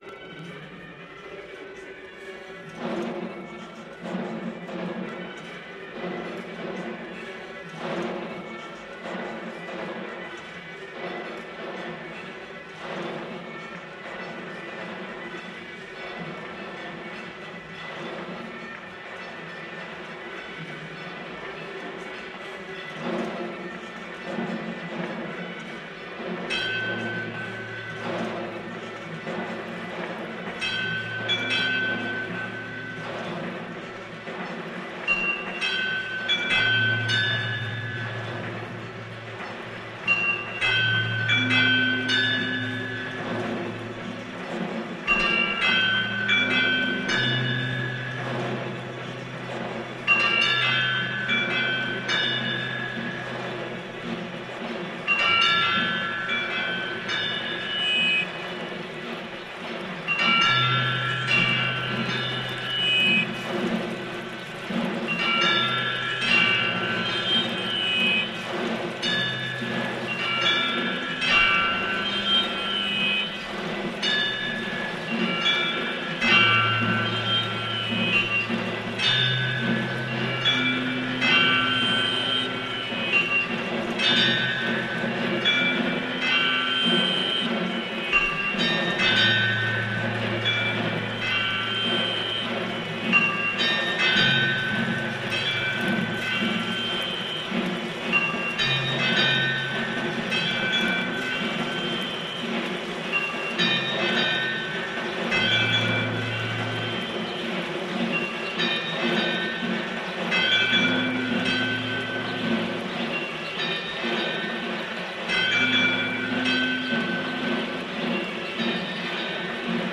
Audio is cut and merged. The drums and drones remain.